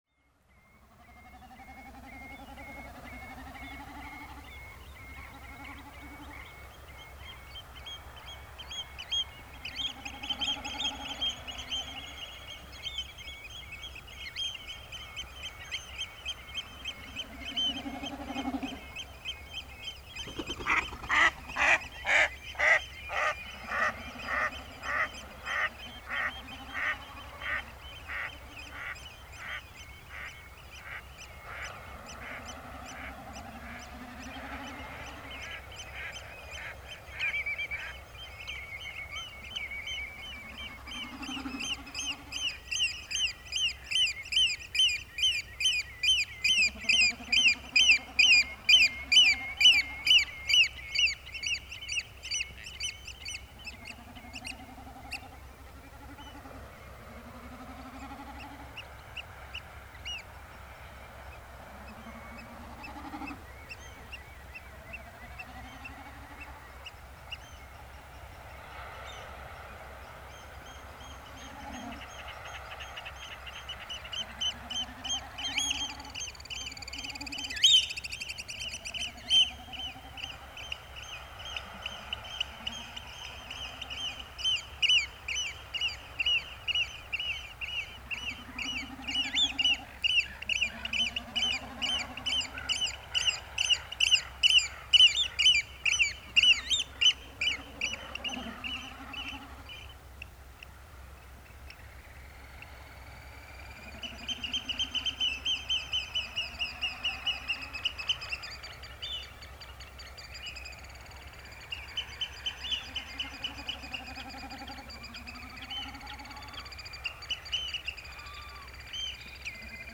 Rétt fyrir miðnætti þriðjudaginn 4. maí fór ég út á Seltjarnarnes.
Hljóðnemunum var komið fyrir í lítilli laut norðan við golfskálann svo að sem minnst heyrðist í bílum sem kæmu út á nesið.
Það sem einkennir þessa upptöku er fjölskrúðugt fuglalíf; vaðfuglar, endur og gæsir og greinilegt að krían er enn ókomin. Hrossagaukurinn er áberandi og hefði getað heyrst betur í honum ef hann hefði ekki haldið sig mestu sunnan við golfskálann á meðan á upptöku stóð. Þá heyrist í regndropum falla sem og af og til í misstórum úthafsöldum skella í fjörunni handan grjótgarðsins sem umlykur Nesið á alla vegu. Upptakan er tekin frá kl. 23:00 til 23:30.